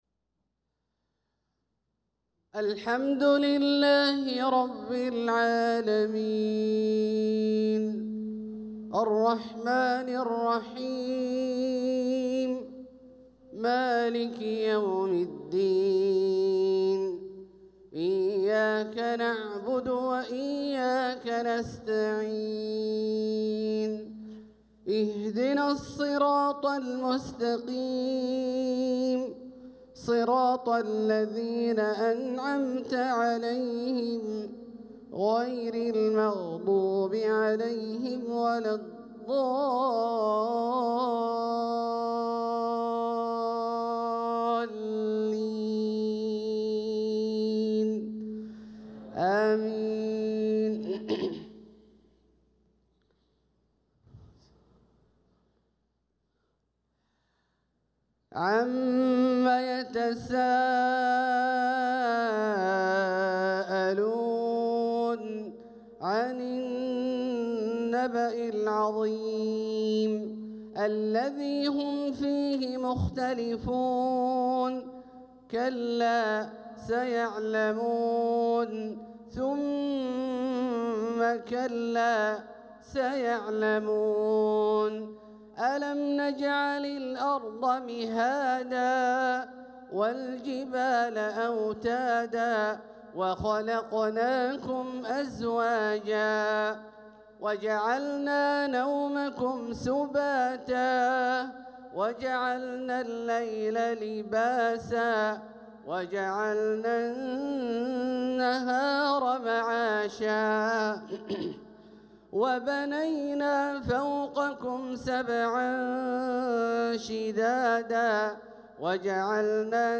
صلاة العشاء للقارئ عبدالله الجهني 5 ربيع الأول 1446 هـ
تِلَاوَات الْحَرَمَيْن .